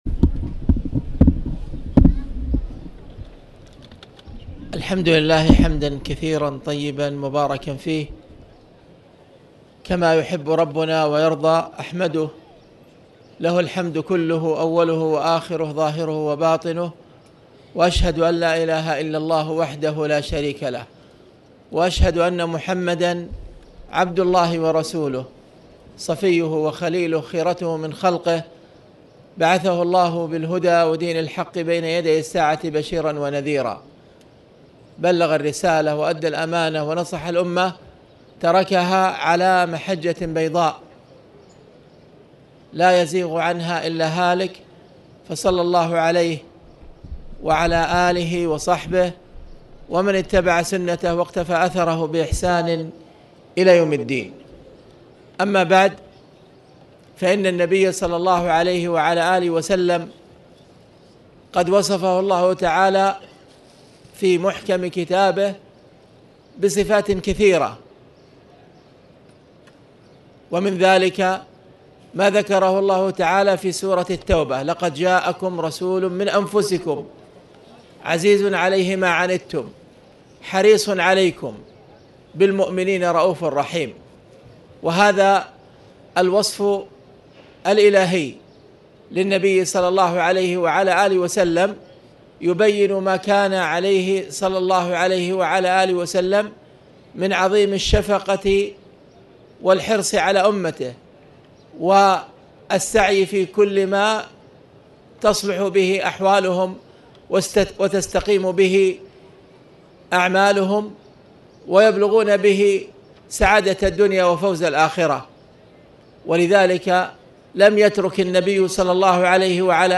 تاريخ النشر ٣ جمادى الأولى ١٤٣٨ هـ المكان: المسجد الحرام الشيخ